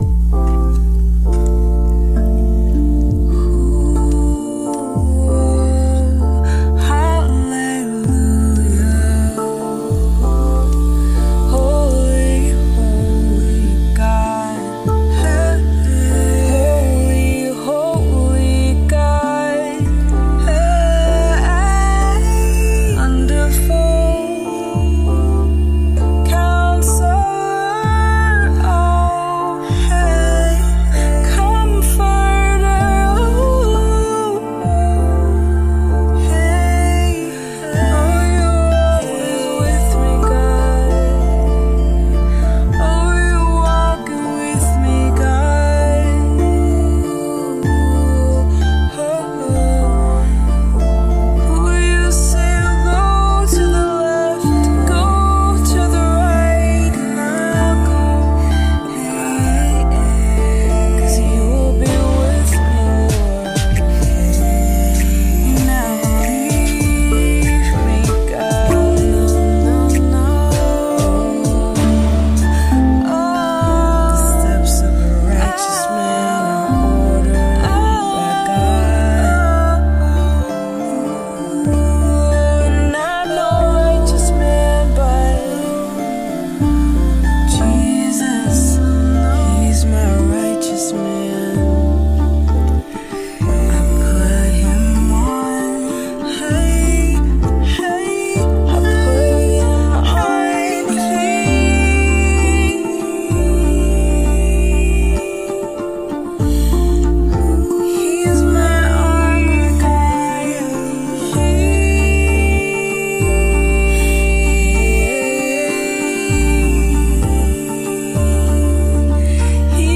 improvised worship
Rav vast drum
spontaneous worship # tongue drum